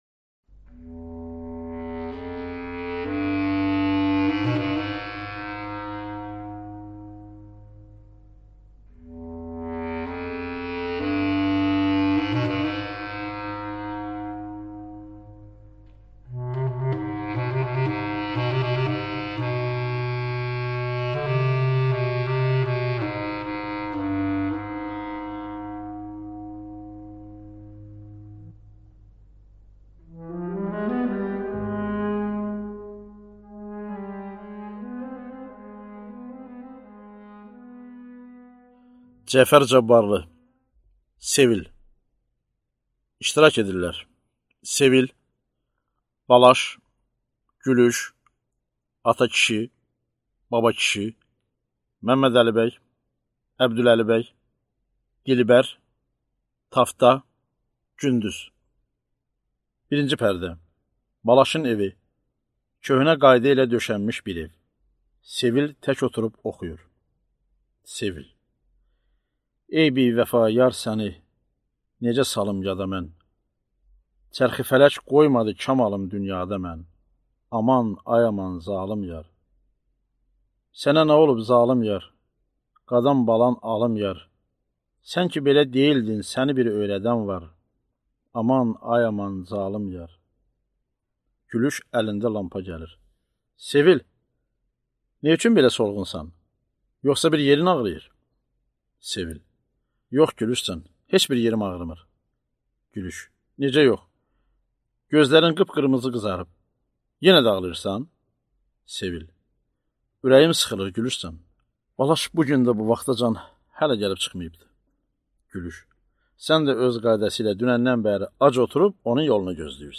Аудиокнига Sevil | Библиотека аудиокниг
Прослушать и бесплатно скачать фрагмент аудиокниги